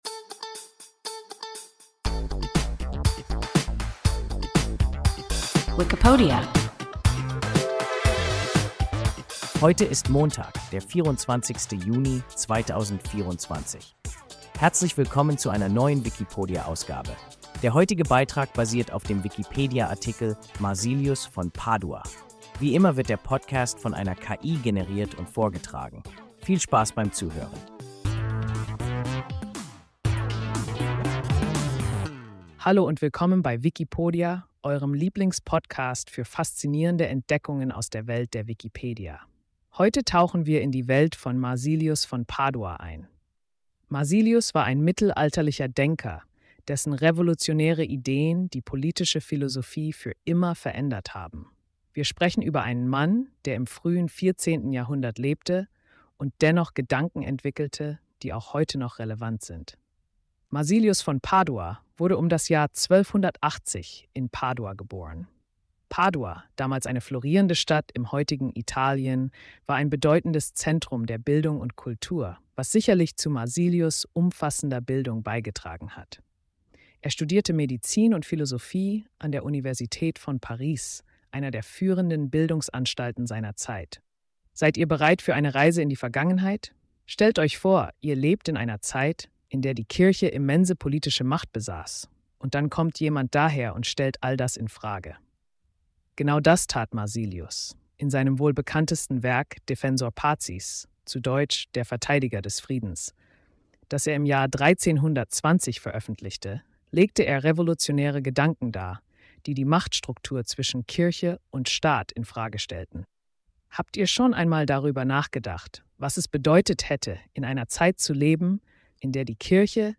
Marsilius von Padua – WIKIPODIA – ein KI Podcast